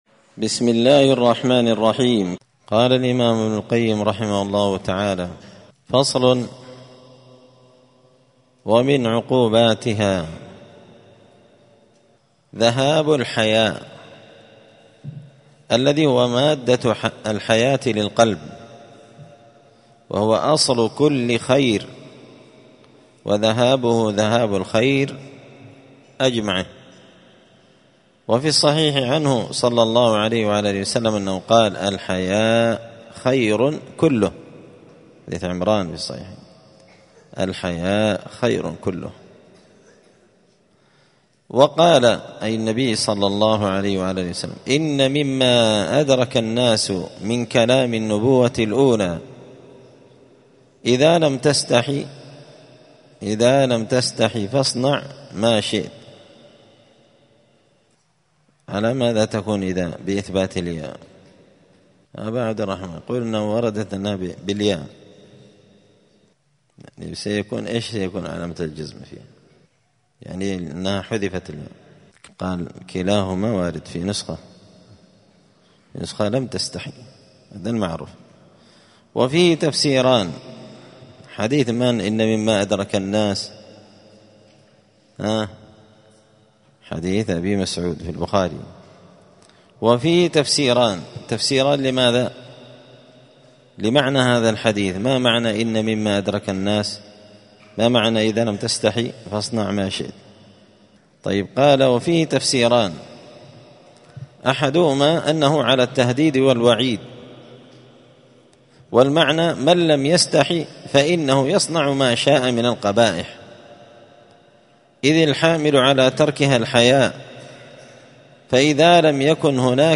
*الدرس الحادي والثلاثون (31) فصل من عقوبات الذنوب والمعاصي أنها تذهب الحياء*